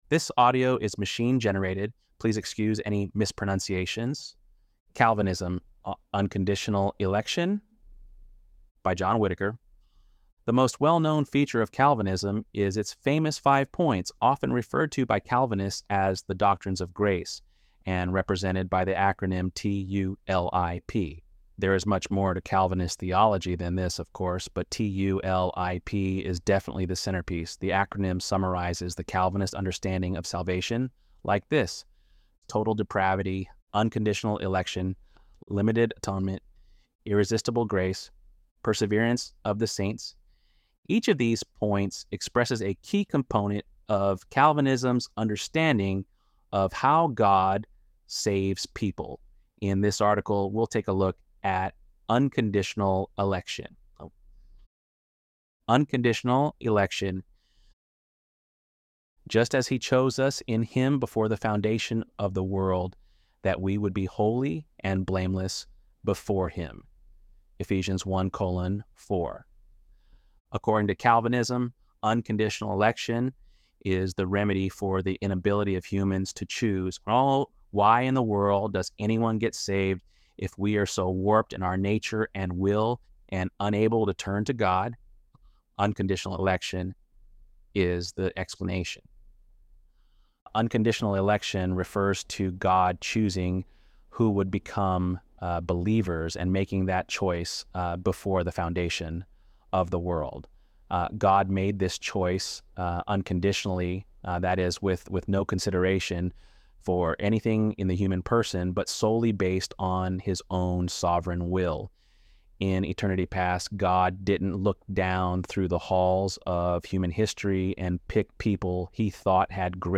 ElevenLabs_5.24_Election_Calvin.mp3